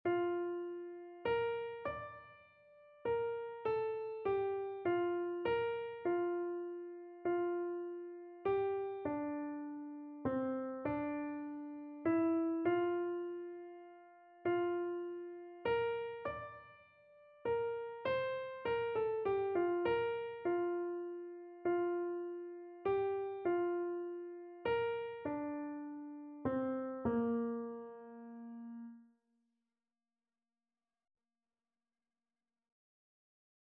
Christian
Free Sheet music for Keyboard (Melody and Chords)
3/4 (View more 3/4 Music)
Keyboard  (View more Easy Keyboard Music)
Classical (View more Classical Keyboard Music)